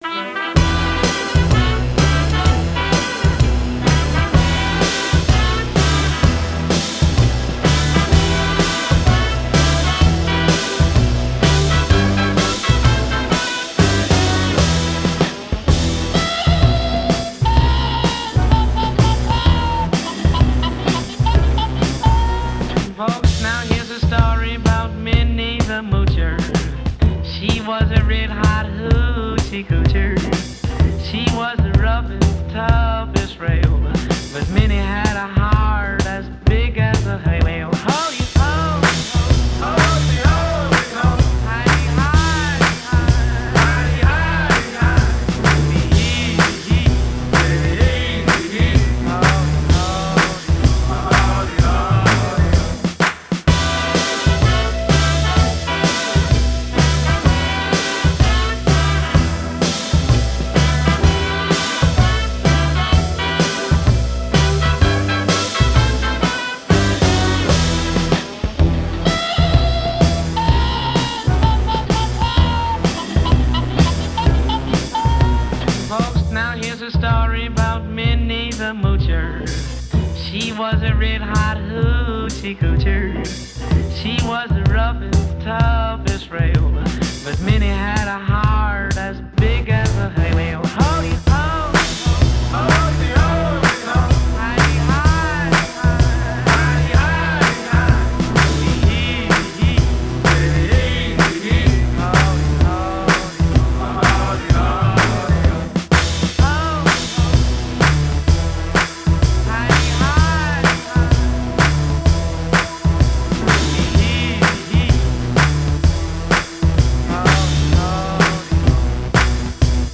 Steampunk music by: